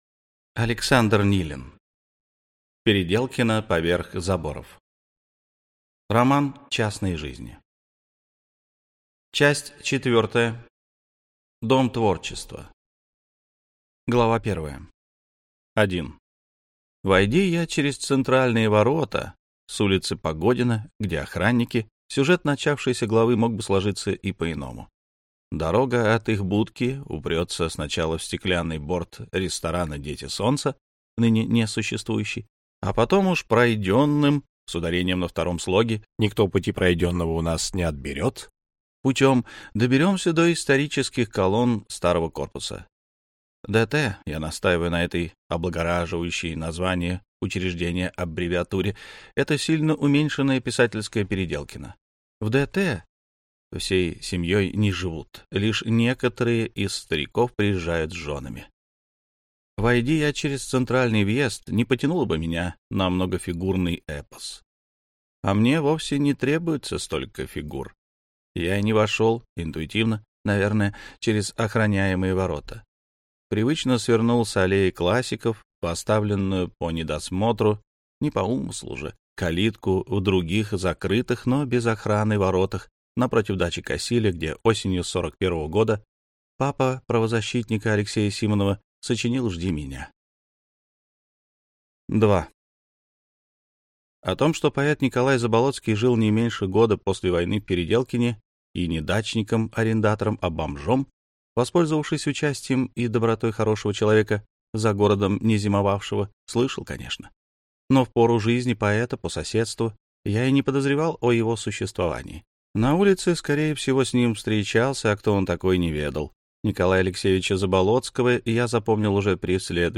Аудиокнига Переделкино: поверх заборов. Часть 4. Дом творчества | Библиотека аудиокниг